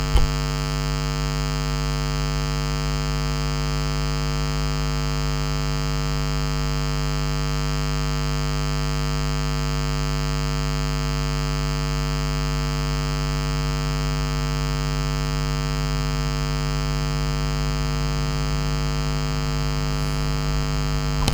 You will hear that tonal buzz modulate to your thinking.
jack-and-jill-with-wireless-headphones.mp3